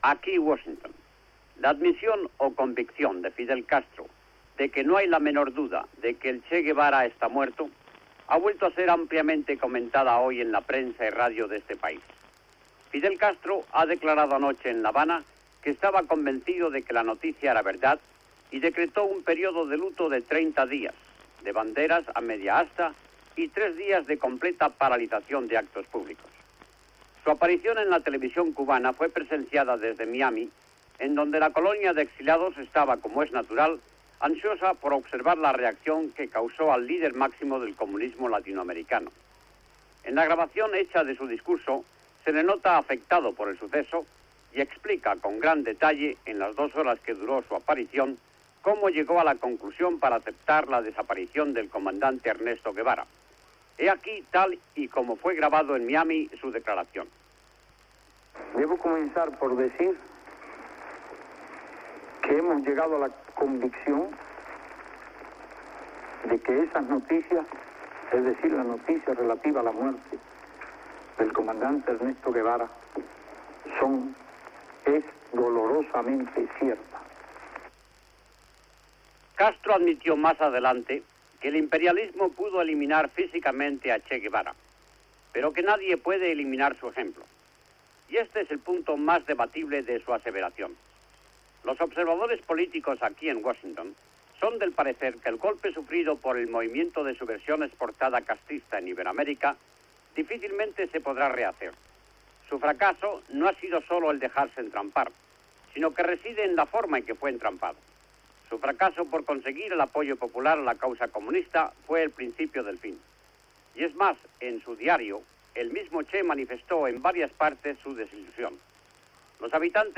Crónica, des dels estudis de la Veu d'Amèrica a Washington (EE.UU.), sobre la confirmació del primer ministre cubà Fidel Castro de de la mort del Che Guevara ( el 9 d'octubre de 1967 a Bolívia)
Informatiu